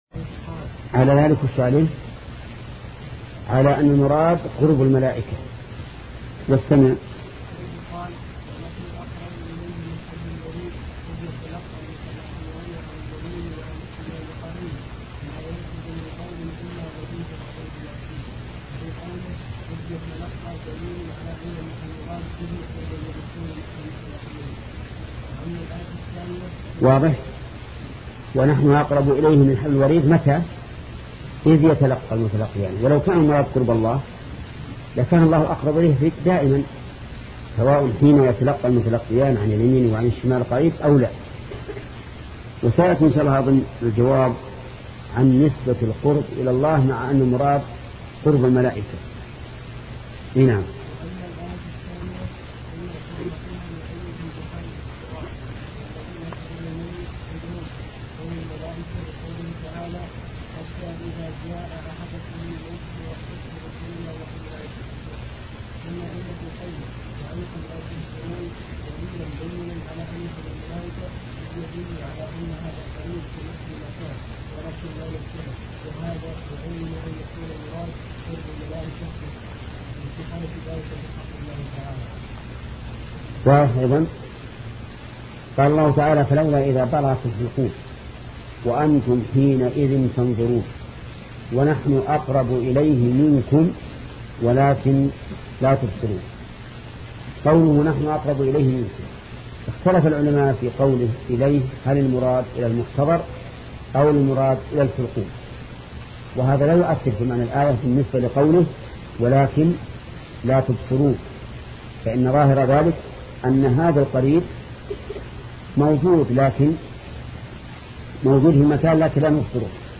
سلسلة مجموعة محاضرات شرح القواعد المثلى لشيخ محمد بن صالح العثيمين رحمة الله تعالى